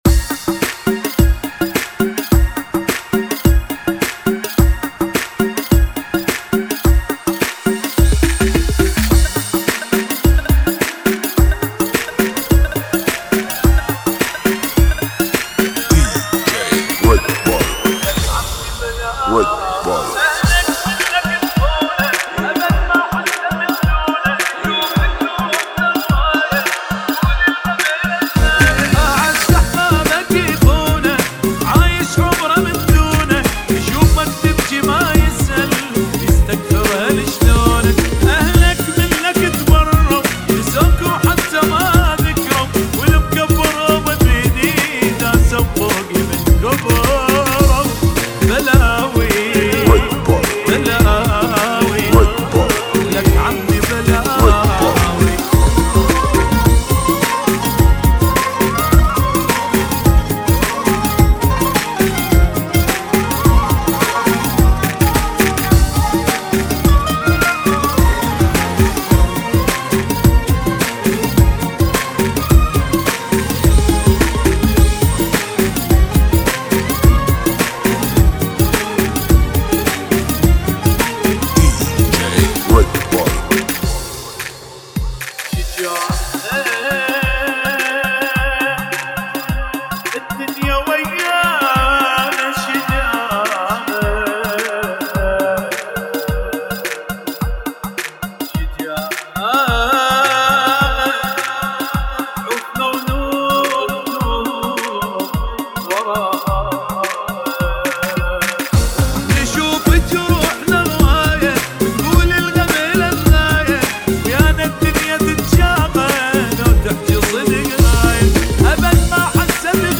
[ 138 bpm ]